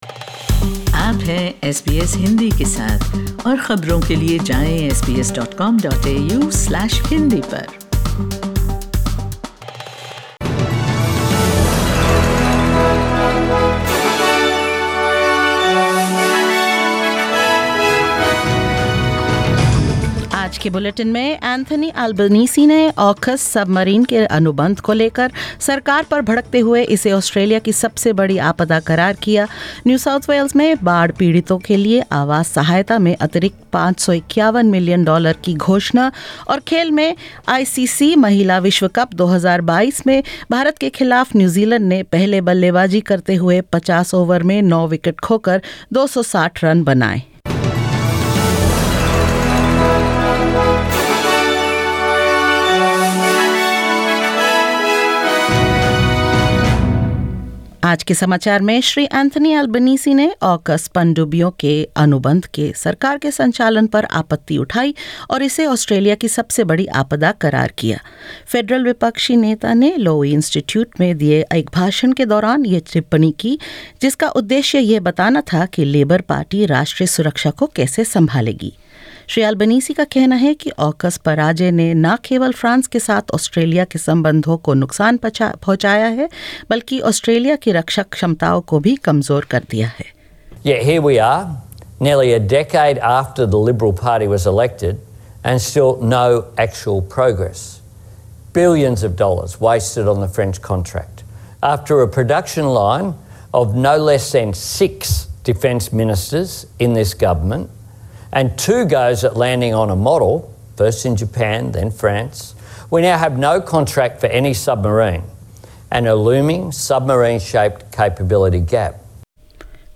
In this latest SBS Hindi bulletin: Opposition leader Anthony Albanese criticises the government's handling of the AUKUS submarines contract; New South Wales government to provide an extra $551 million in housing support for flood victims and more.